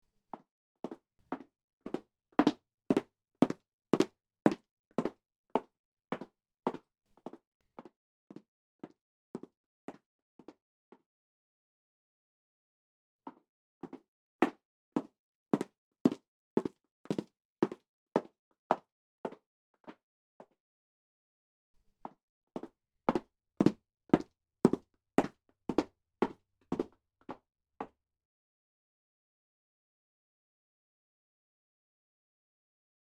Звук каблуков
13. Звук проходящей или идущей мимо женщины в туфлях на высоком каблуке по деревянному полу, с права на лево и обратно
jenshina-prohodit-mimo-1.mp3